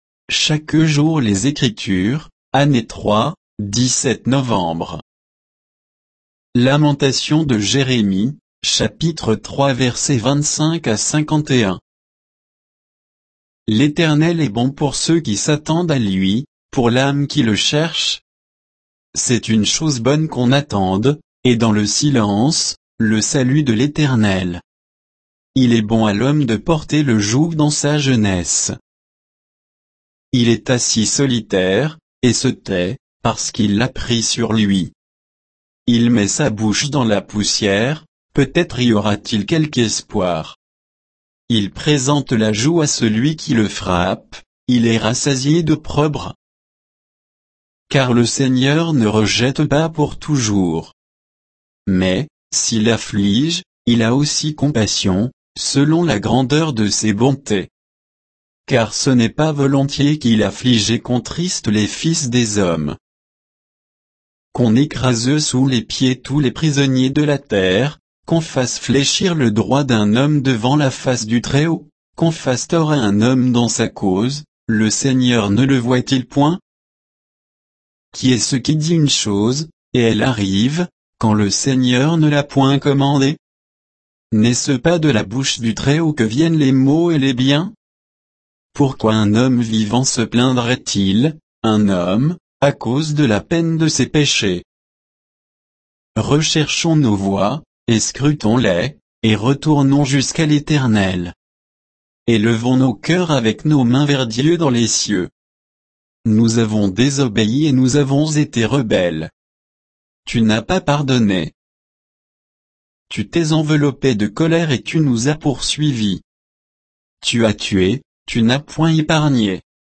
Méditation quoditienne de Chaque jour les Écritures sur Lamentations de Jérémie 3